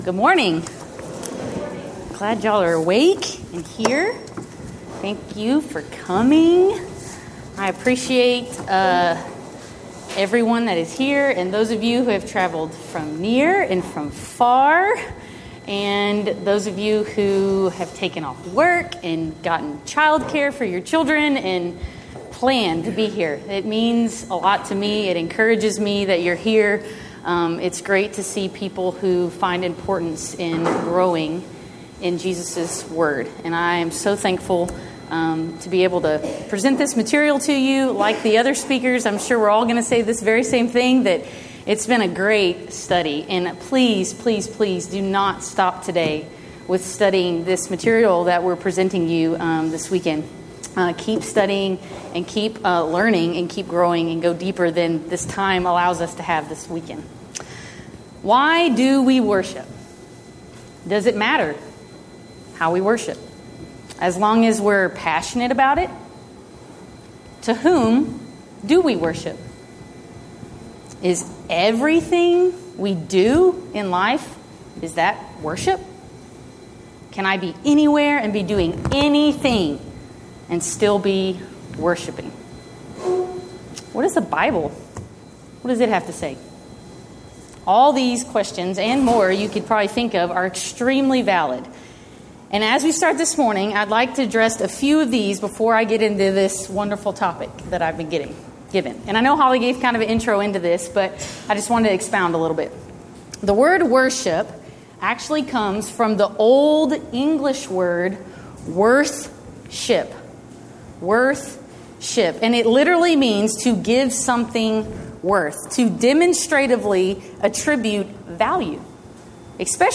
Event: 5th Annual Women of Valor Ladies Retreat
Ladies Sessions